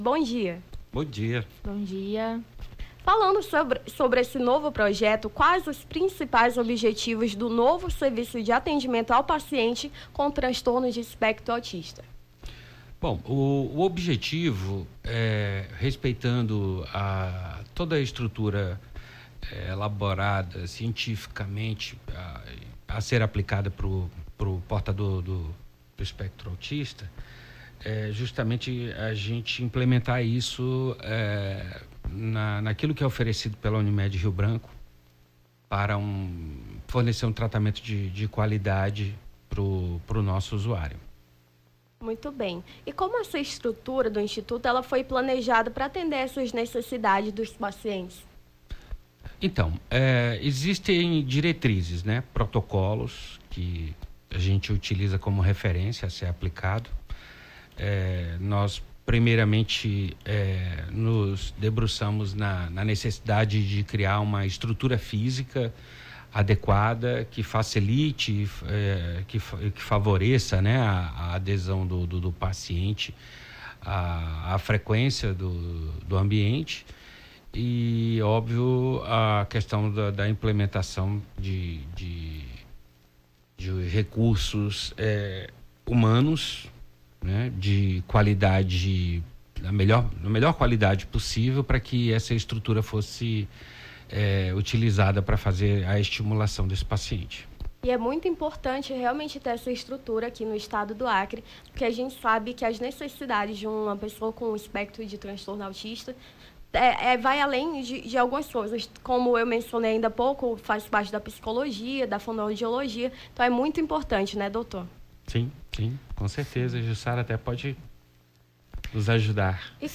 Nome do Artista - CENSURA - ENTREVISTA (ATENDIMENTO PACIENTE TEA) 19-06-23.mp3